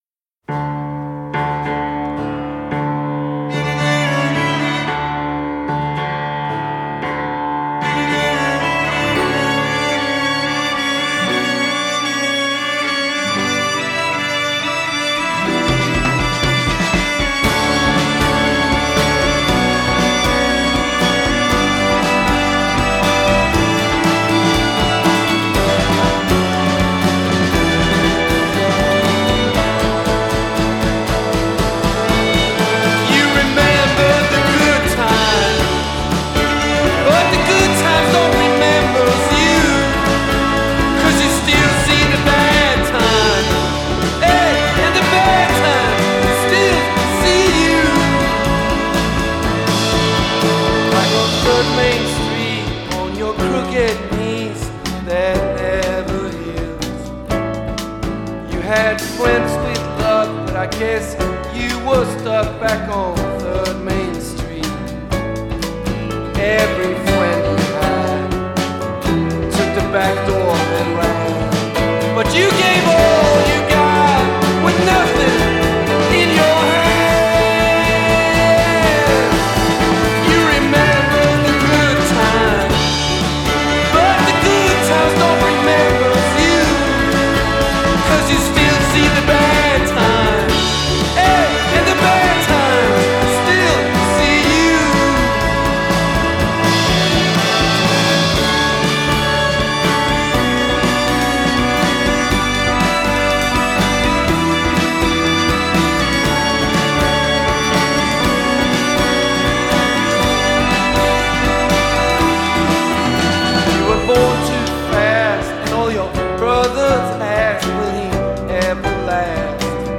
Il suo epico e sentimentale secondo album